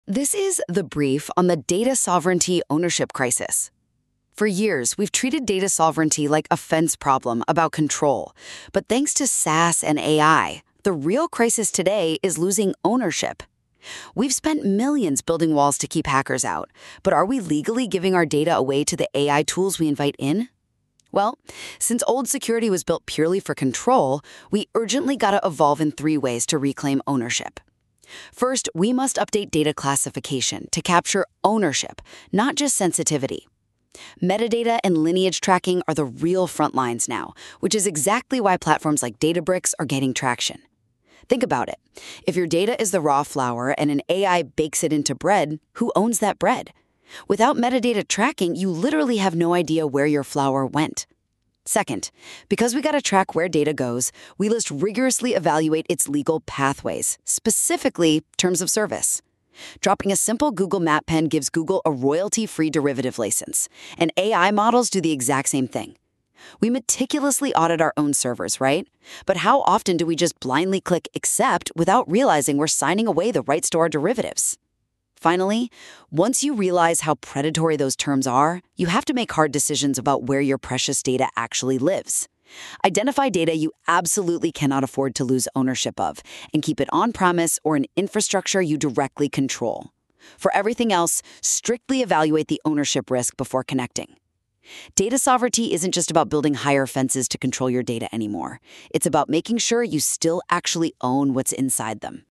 Short on time? 100-second audio summary…
A speedy listen, generated using Google Notebook LM.